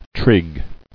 [trig]